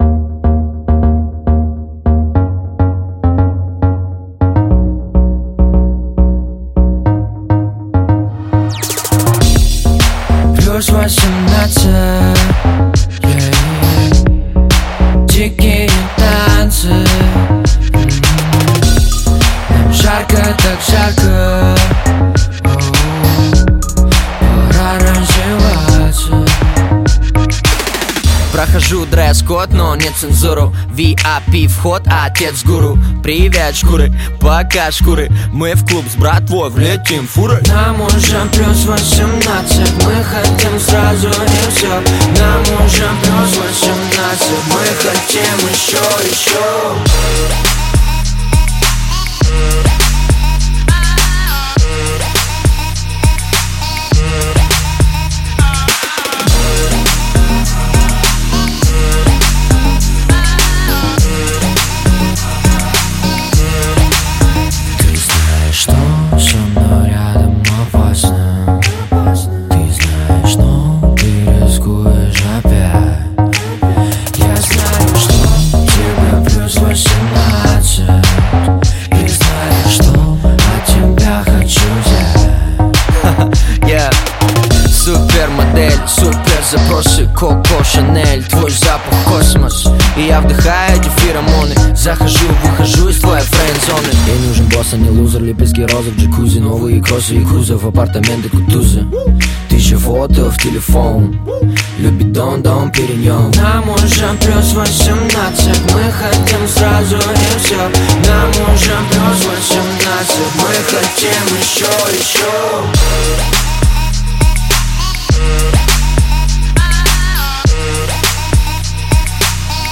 Жанр: Жанры / Электроника